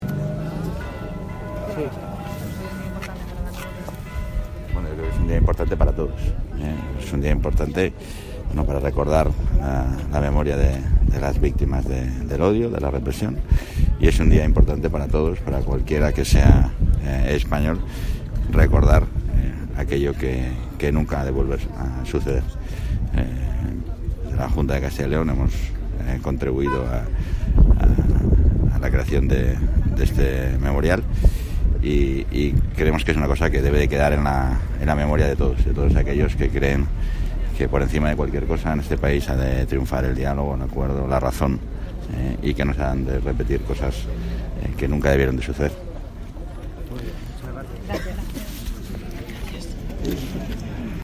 Declaraciones del vicepresidente Igea, previas al acto de memoria histórica celebrado hoy en Valladolid